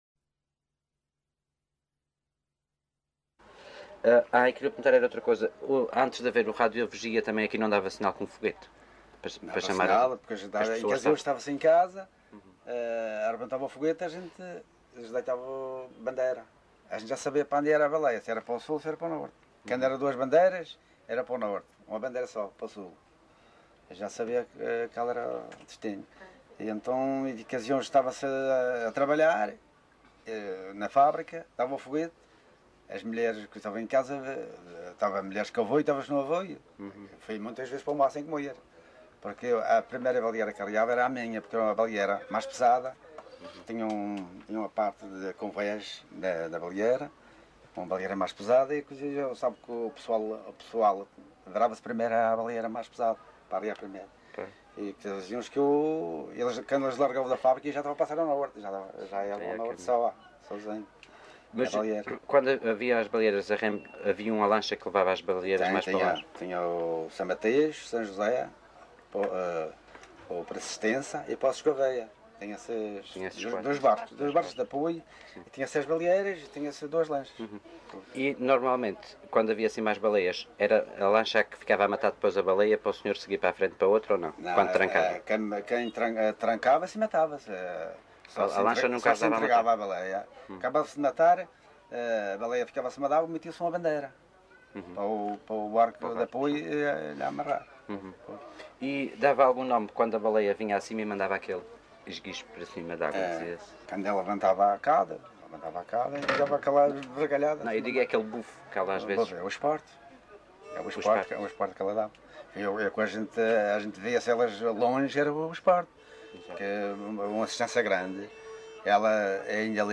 LocalidadeCaniçal (Machico, Funchal)